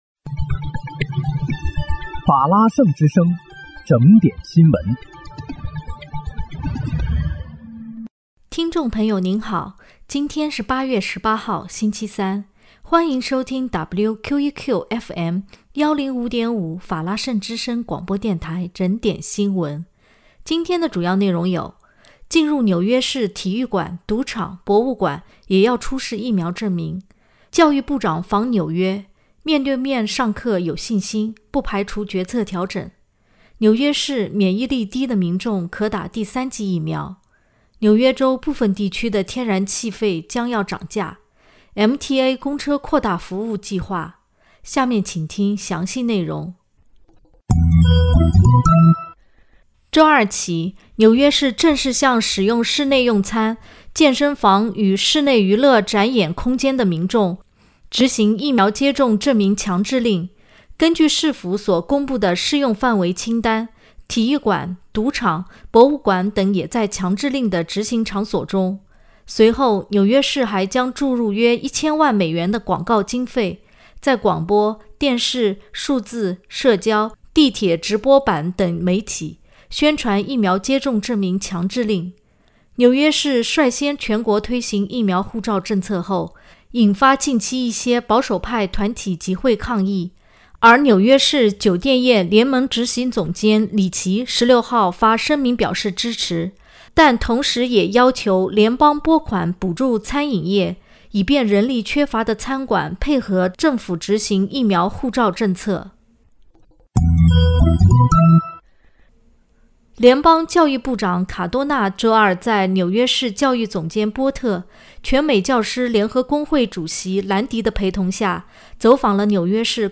8月18日（星期三）纽约整点新闻